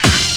SWINGSTAB 1.wav